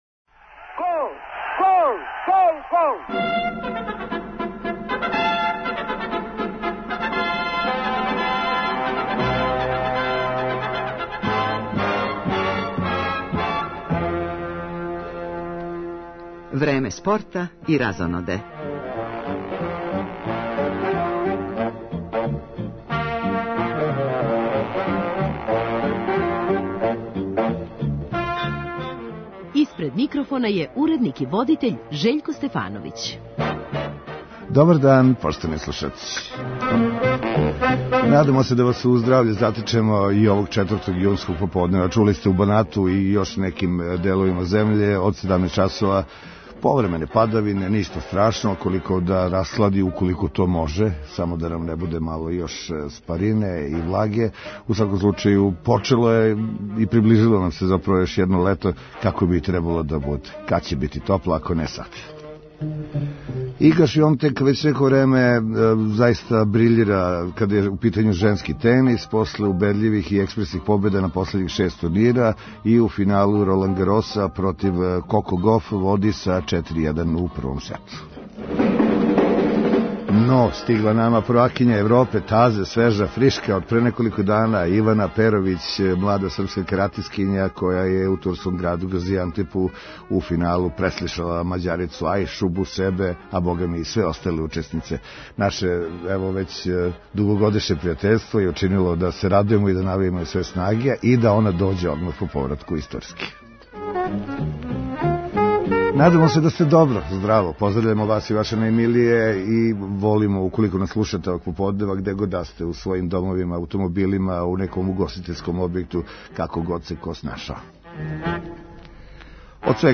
Данас ће с нама провести део суботњег поподнева, у студију Радио Београда 1.